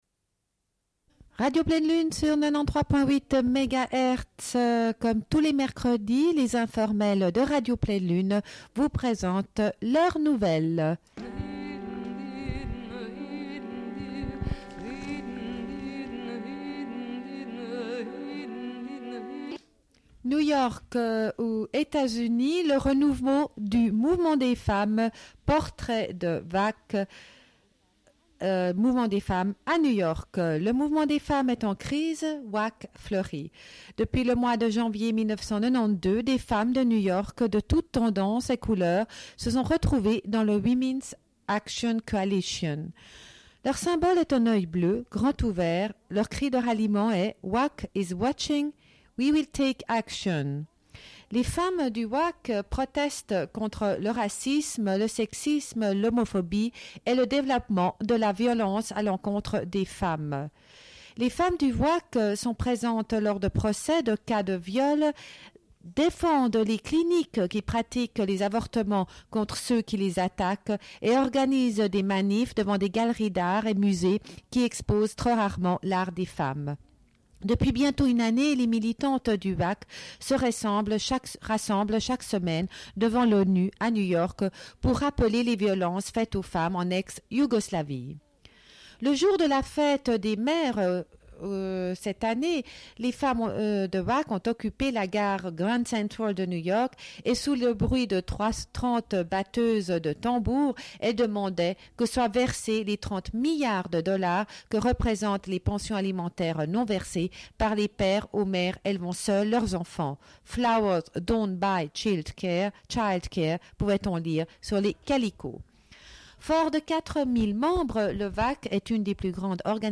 Bulletin d'information de Radio Pleine Lune du 24.11.1993 - Archives contestataires